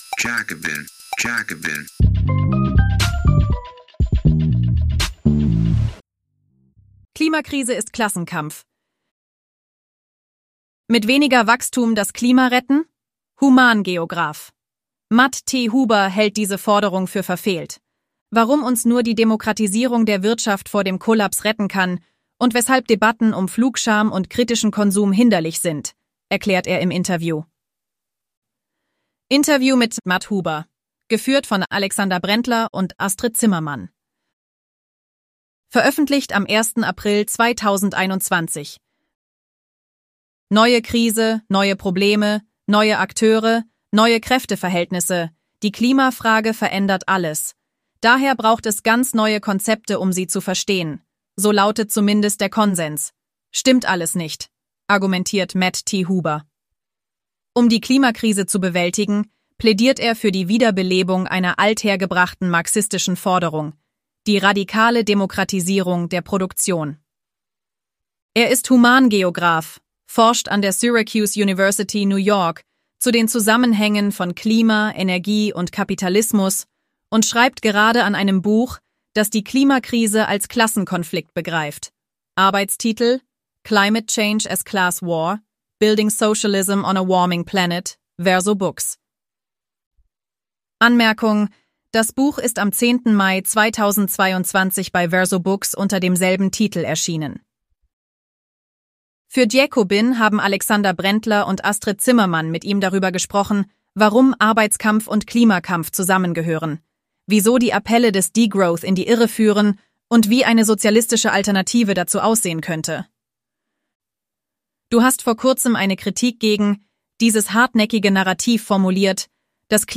Warum uns nur die Demokratisierung der Wirtschaft vor dem Kollaps retten kann und weshalb Debatten um Flugscham und kritischen Konsum hinderlich sind, erklärt er im Interview.